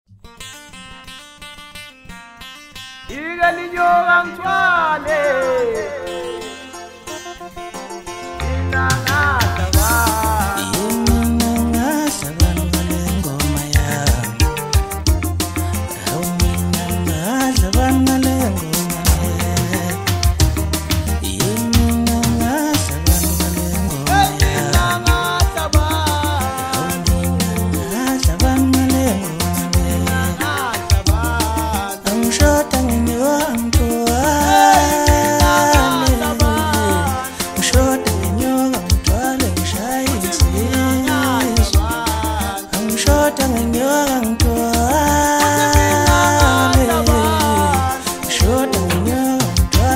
Home » Hip Hop » Latest Mix » Maskandi